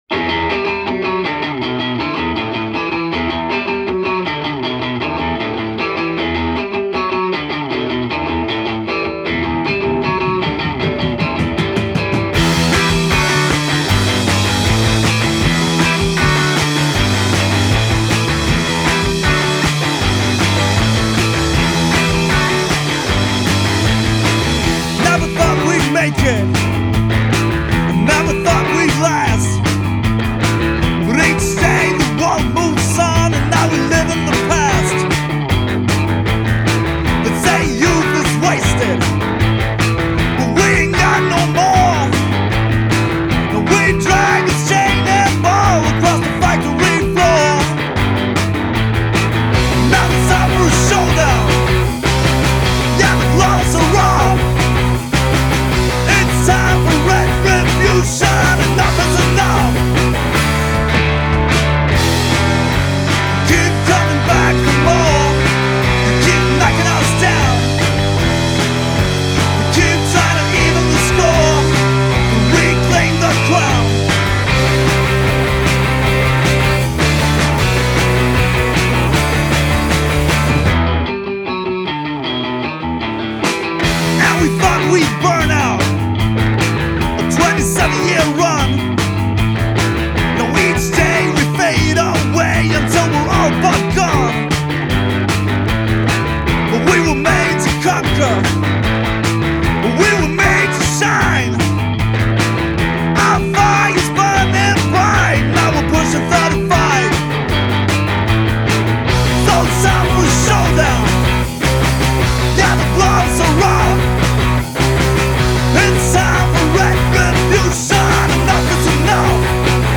Genre: Punk/Hardcore